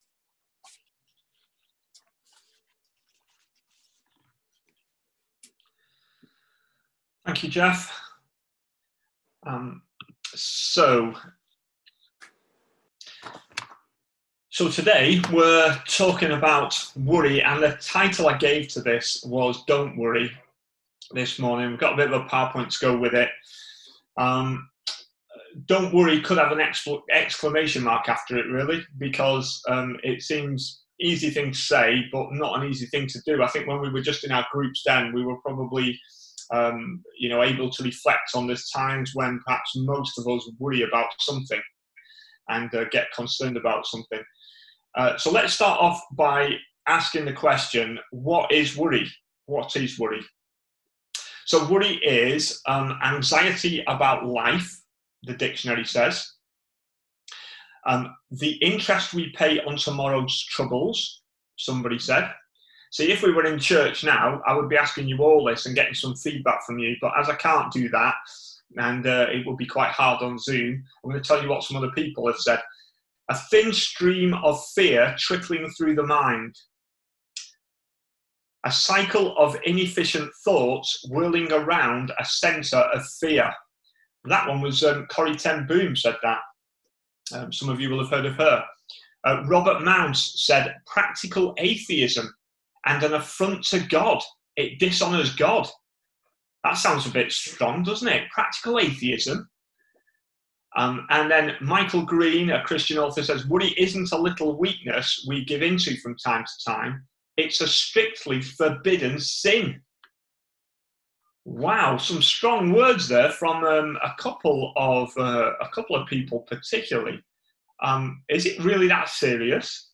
A sermon preached on 16th August, 2020.